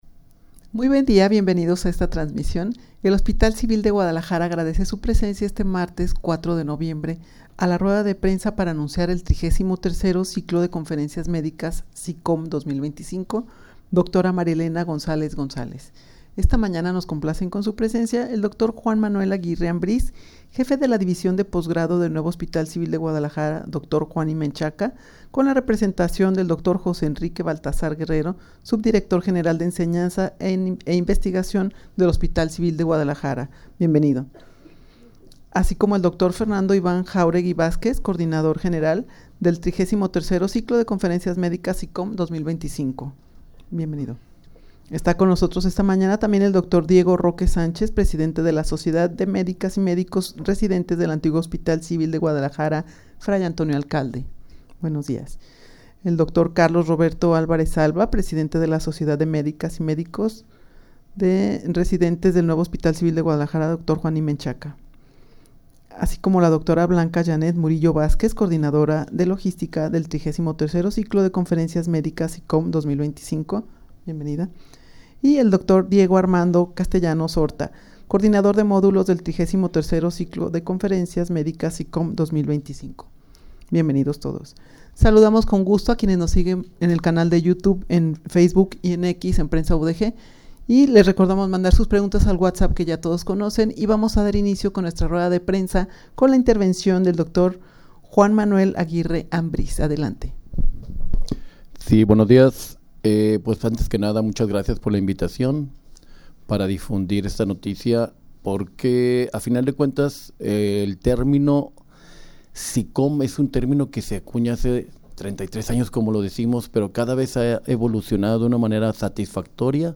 Audio de la Rueda de Prensa
rueda-de-prensa-para-anunciar-el-xxxiii-ciclo-de-conferencias-medicas.mp3